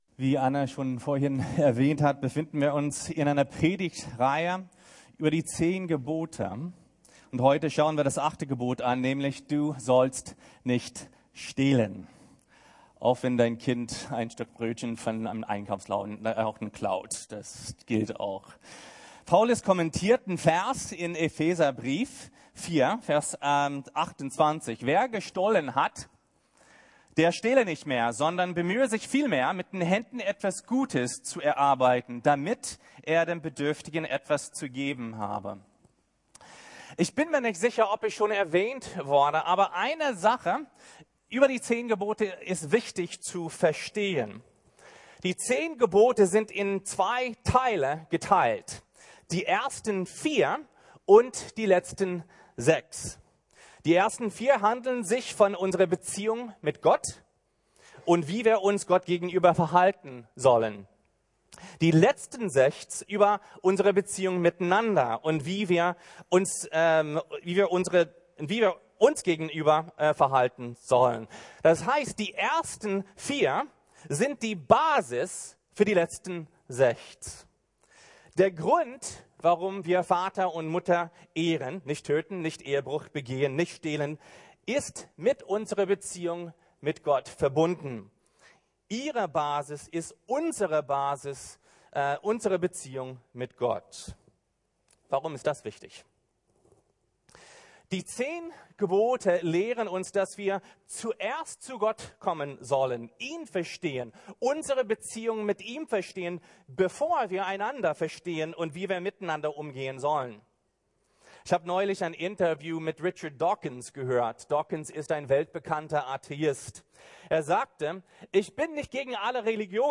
10 Worte des Lebens - 8 - Du sollst nicht stehlen ~ Predigten der LUKAS GEMEINDE Podcast